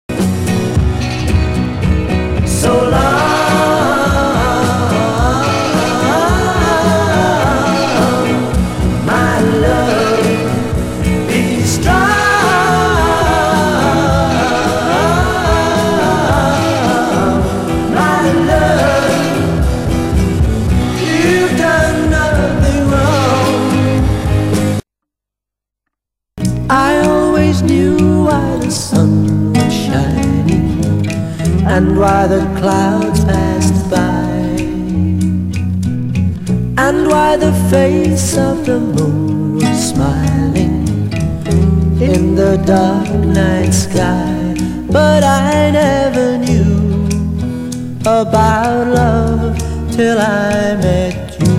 全体的にモワッとした（？）雰囲気にはサイケに近い感覚を覚える。
B面はメンバー全員での共作による、ギターの洒落た音色とゆったりしたリズムが印象的な小品風。
(税込￥1980)   BEAT